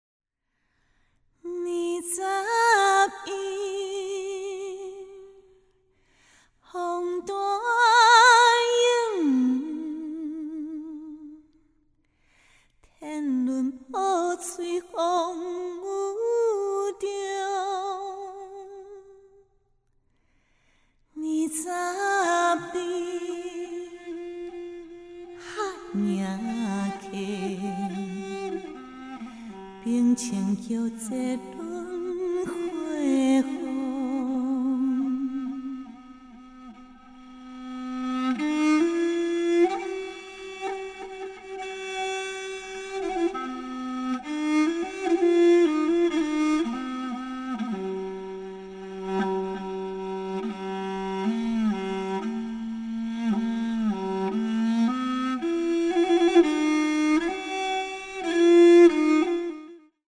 現代南管